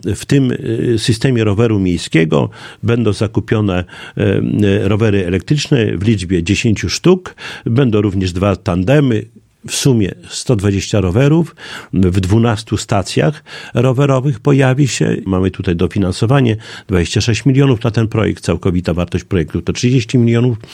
O szczegółach Czesław Renkiewicz, prezydent Suwałk.